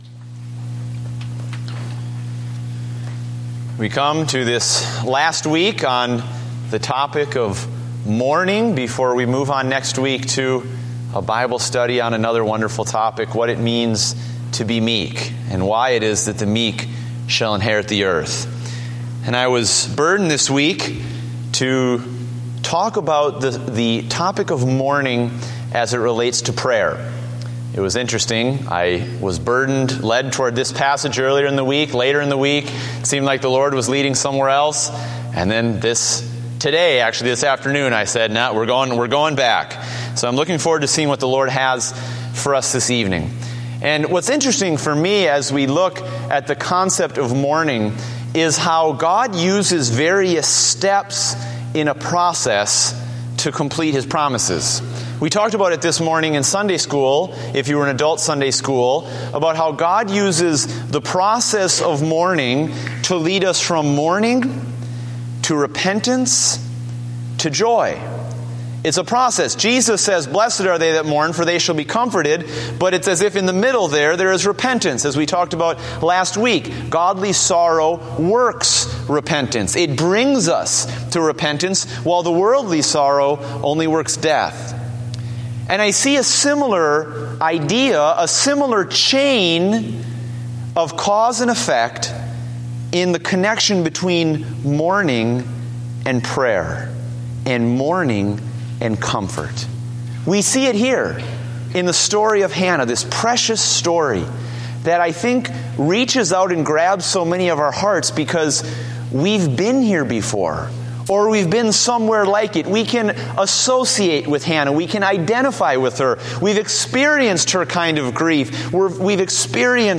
Date: March 22, 2015 (Evening Service)